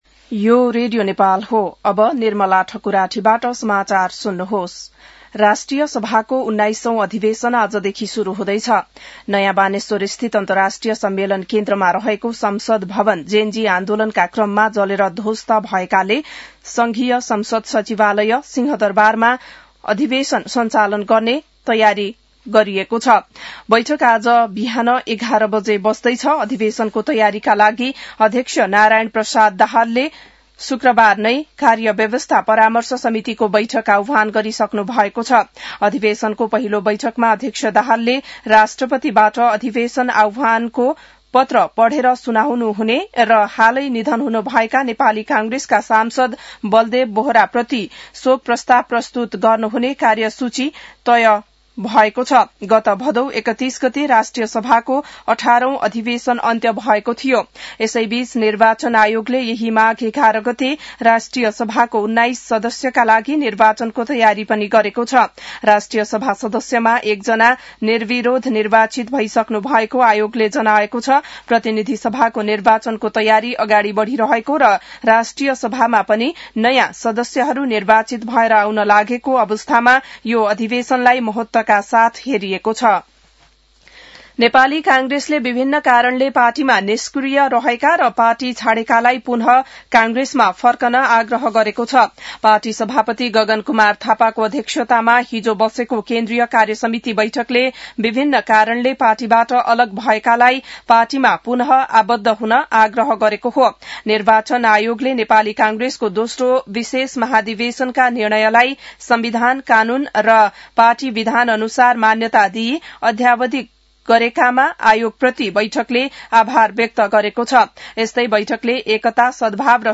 बिहान १० बजेको नेपाली समाचार : ४ माघ , २०८२